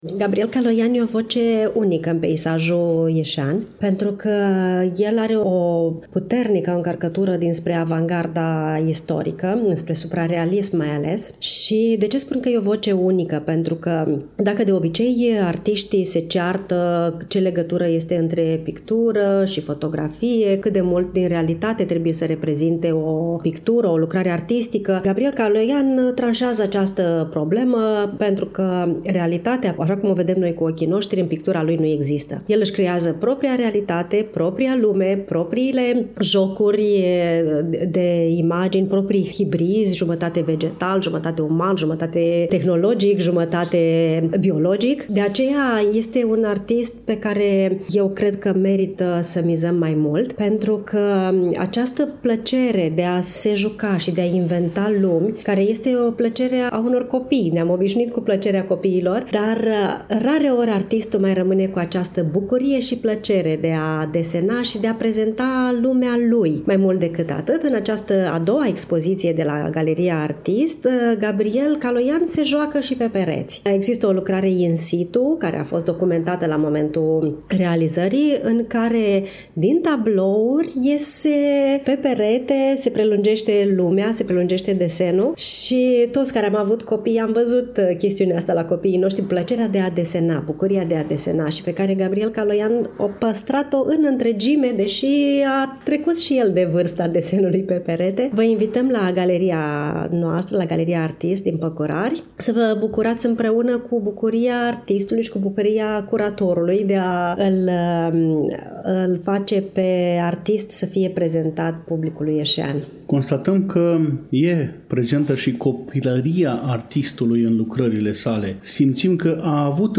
În ziua de 23 octombrie 2025, începând cu ora 18, am participat la finisajul expoziției Căutând sateliții (Looking for satellites), manifestare desfășurată, la Iași, în incinta Galeriei ArtEast de pe Strada Păcurari, Numărul 8.